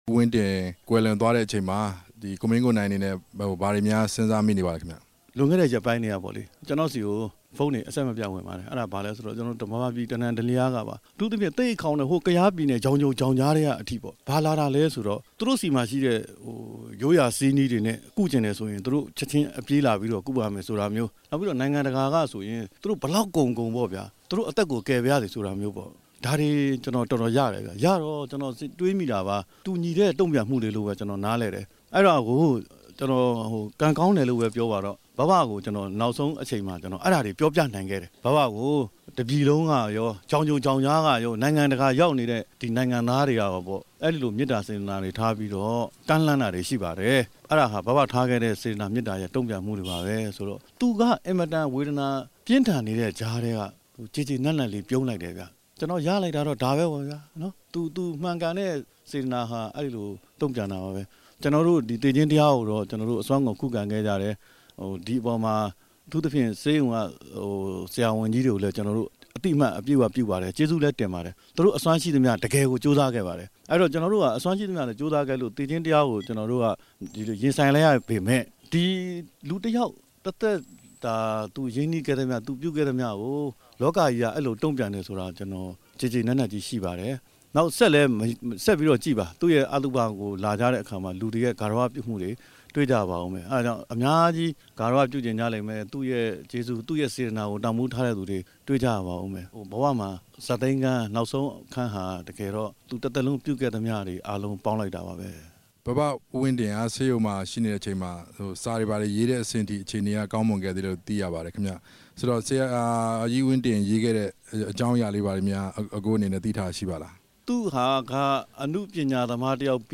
ကိုမင်းကိုနိုင်ကို တွေ့ဆုံမေးမြန်းချက်